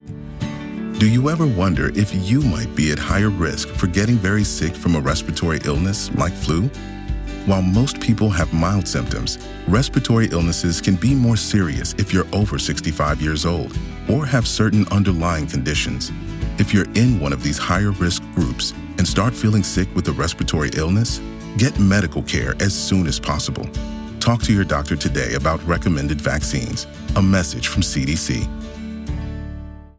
Public service announcements (PSA)